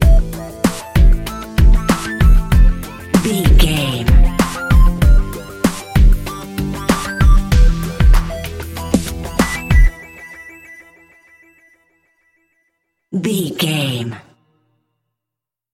Aeolian/Minor
drums
electric piano
strings
funky
aggressive
hard hitting